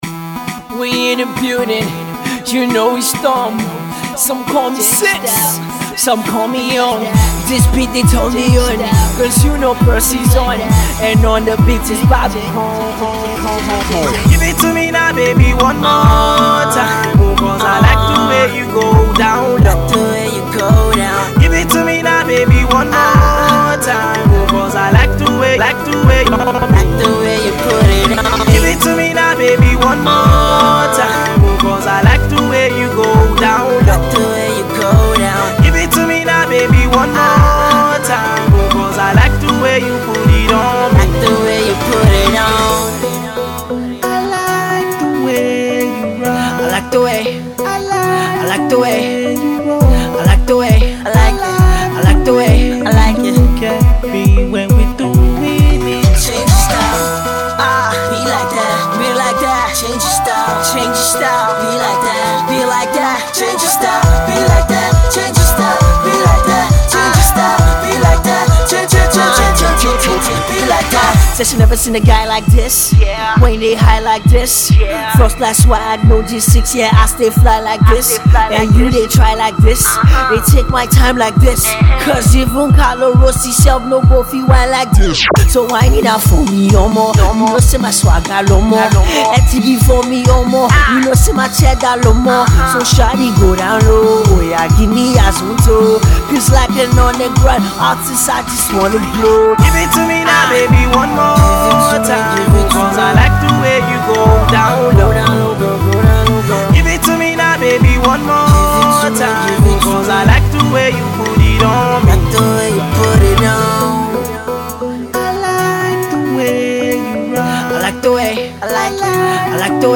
club track